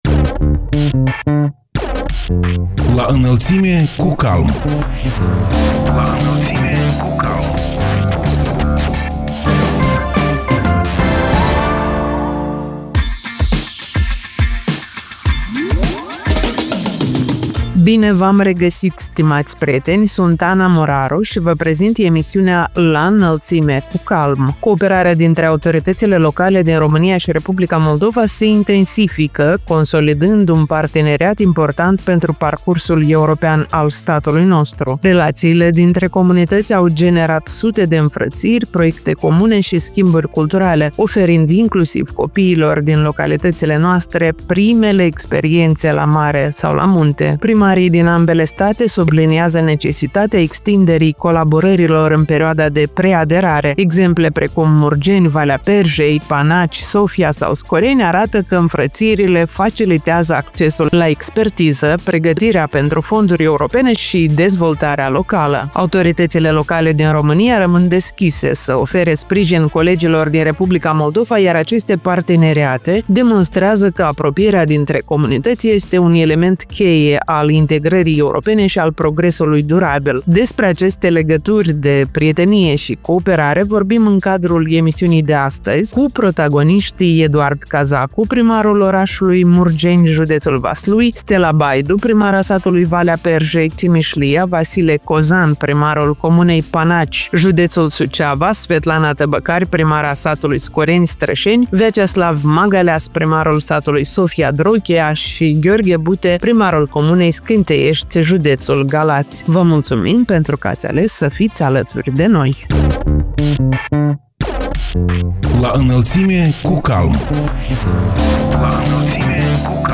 Despre aceste legături de prietenie și cooperare vorbim în cadrul emisiunii „La Înălțime cu CALM”. Protagoniștii ediției sunt: Eduard Cazacu, primarul orașului Murgeni, județul Vaslui; Stela Baidu, primara satului Valea Perjei, Cimișlia; Vasile Cozan, primarul comunei Panaci, județul Suceava; Svetlana Tăbăcari, primara satului Scoreni, Strășeni; Veaceslav Magaleas, primarul satului Sofia, Drochia și Gheorghe Bute, primarul comunei Scânteiești, județul Galați.